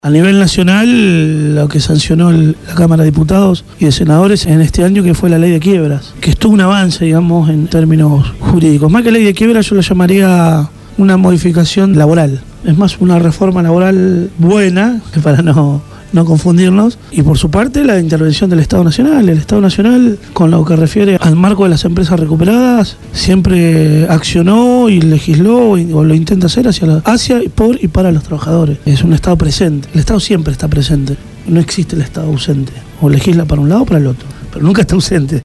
habló esta mañana en el programa Punto de Partida de Radio Gráfica sobre el veto del Jefe de Gobierno porteño, Mauricio Macri, a la Ley 4008 de protección a las fábricas recuperadas.